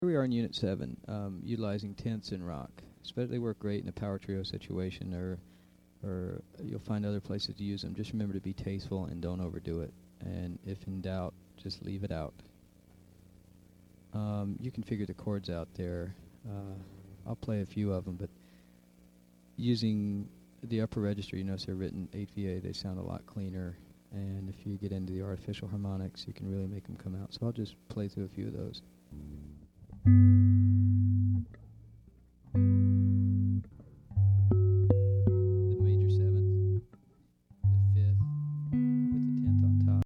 Voicing: Electric Bass